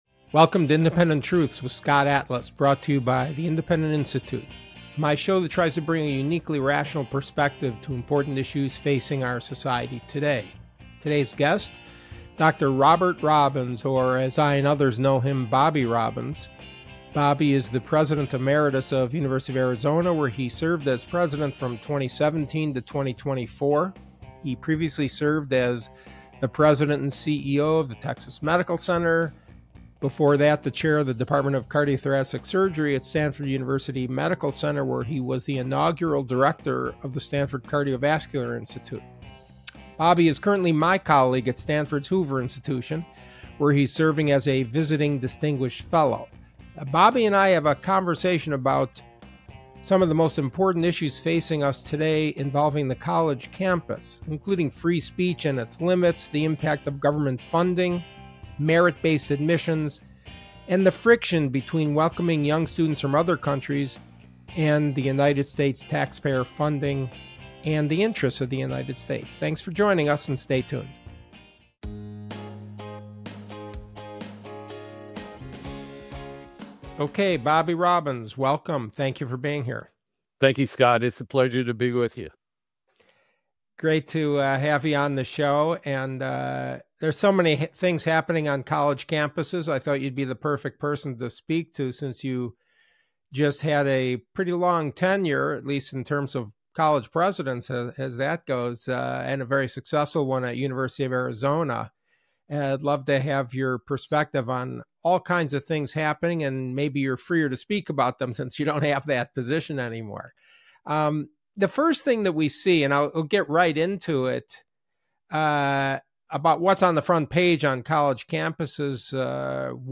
1 (ICYMI) Bishop Mariann Edgar Budde on Faith, Politics, and Ethical Conversations in a Polarized Nation 1:06:54 Play Pause 2h ago 1:06:54 Play Pause Play later Play later Lists Like Liked 1:06:54 This was such a refreshing conversation with Bishop Mariann Edgar Budde.